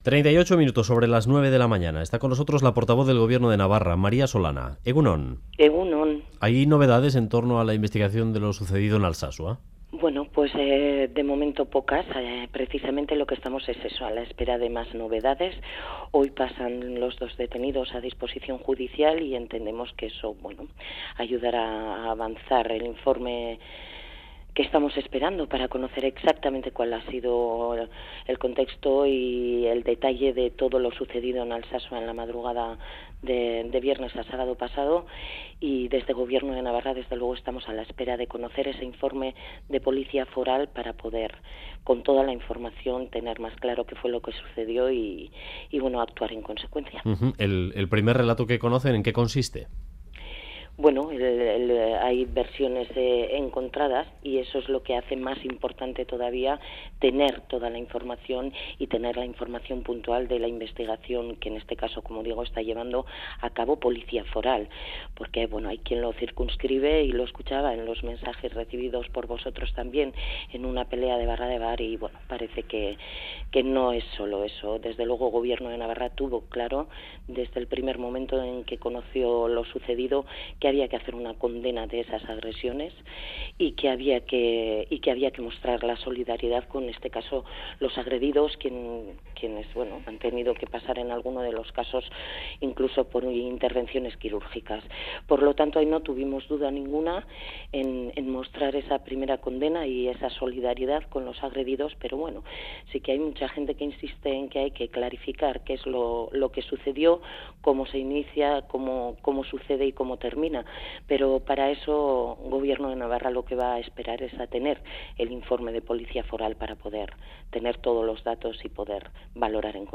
Radio Euskadi BOULEVARD María Solana: 'No parece que fuera sólo una pelea de barra de bar' Última actualización: 17/10/2016 10:12 (UTC+2) La portavoz del gobierno Navarro afirma que el ejecutivo tuvo claro desde el principio que tenía que condenar los hechos porque su obligación es propiciar un espacio de convivencia y tolerancia.